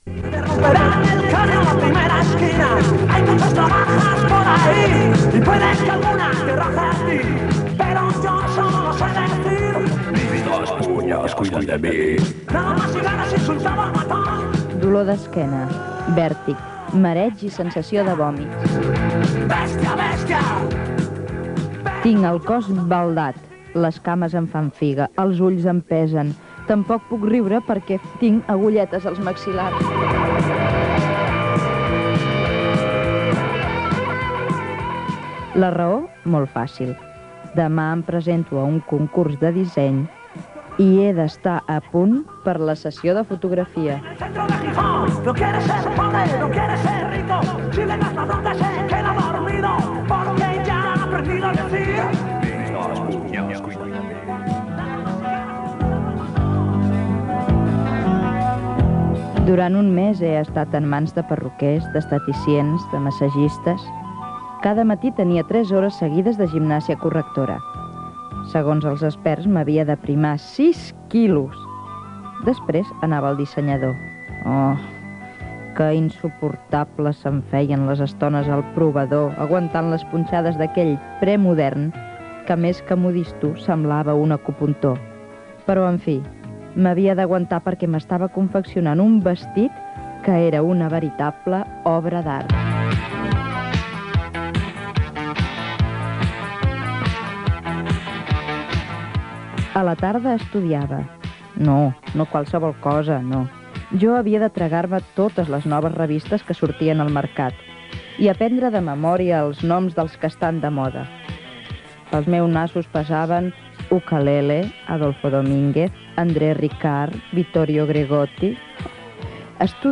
Reportatge sobre el disseny de moda